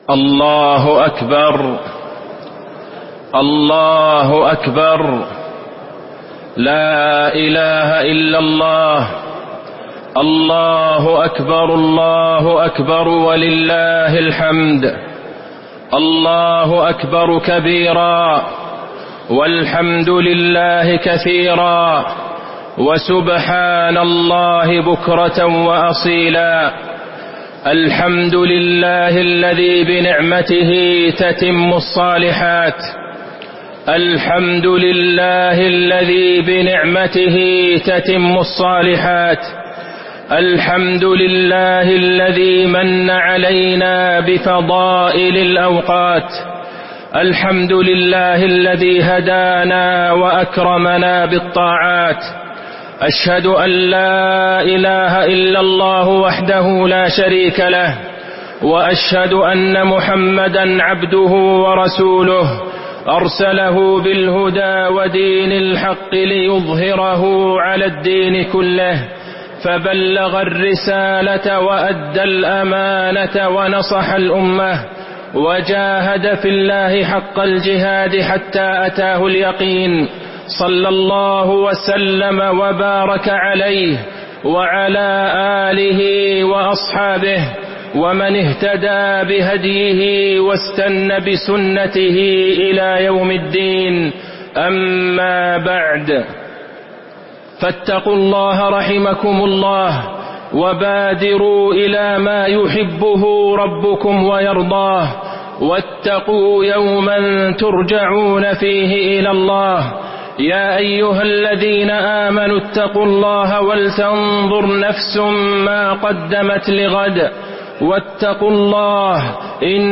خطبة عيد الفطر- المدينة - الشيخ عبدالله البعيجان - الموقع الرسمي لرئاسة الشؤون الدينية بالمسجد النبوي والمسجد الحرام
المكان: المسجد النبوي